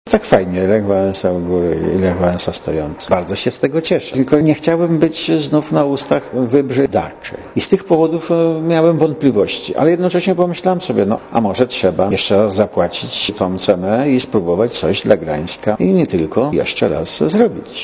"Jeśli mogę coś jeszcze dla Gdańska zrobić, to czemu nie – powiedział Radiu ZET były prezydent.
Komentarz audio